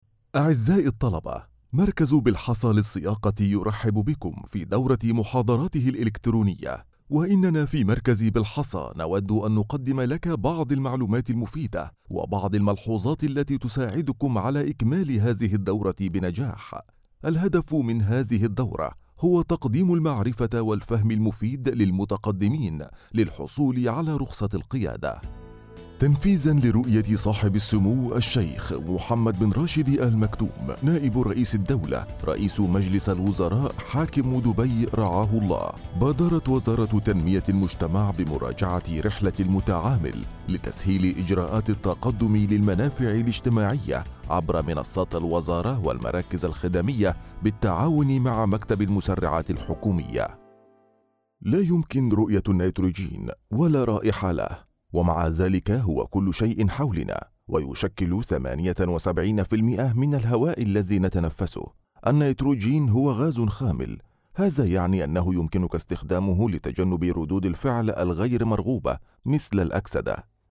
Adult
Has Own Studio
Warm and deep narrative voice, smooth and professional in corporate presentations and explainer videos, and also is have magic voice for commercials projects
Egyptian
audio description
commercial